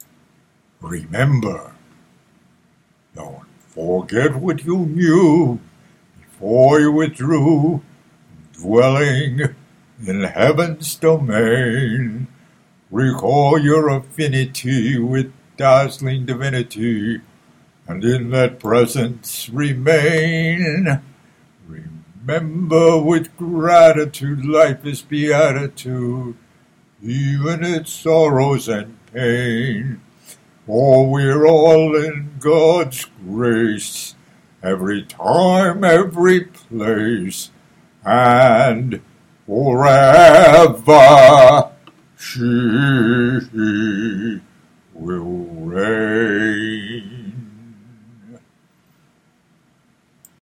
Thus today’s “Egocide” posting includes above key quotations, and the following sutra-poem (with mp3 recitation), which was composed after I’d realized the crucial importance of undoing ego to transcend inevitable karmic suffering in this illusory low energy Third Dimension (3D) space/time causality duality ‘reality’.